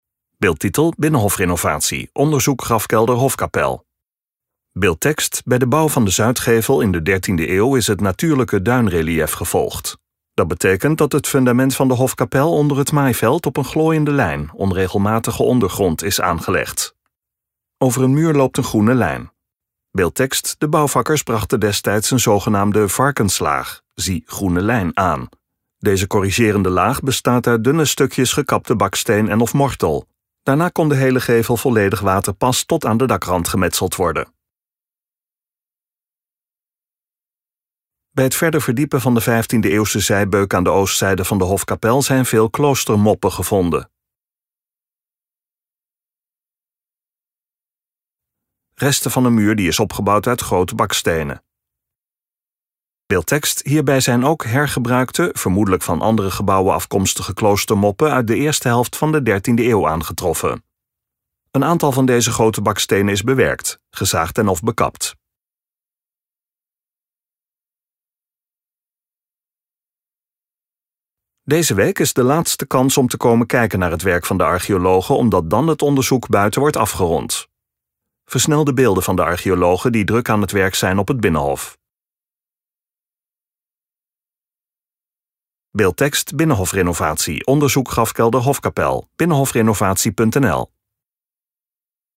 OPGEWEKTE MUZIEK
DE OPGEWEKTE MUZIEK SPEELT VERDER TOT HET EIND VAN DE VIDEO